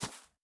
Shoe Step Grass Medium D.wav